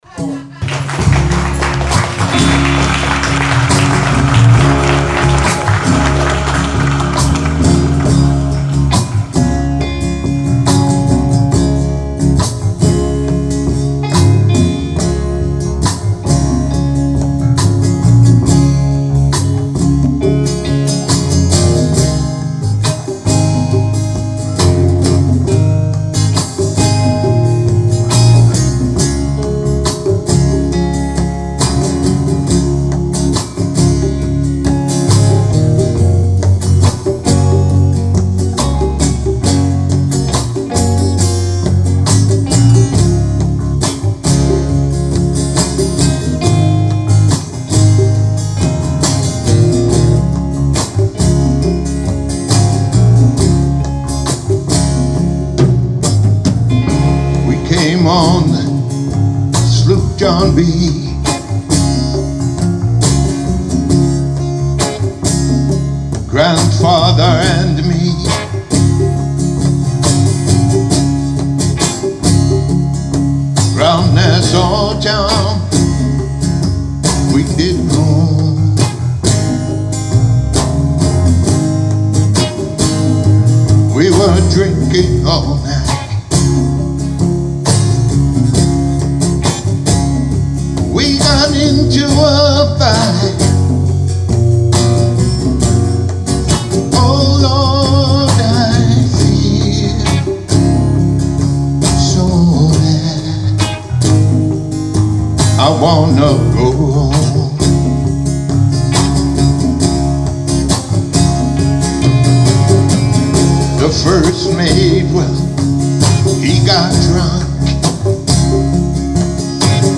Two More From the “LIVE album ” Shake A Bum”.